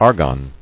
[ar·gon]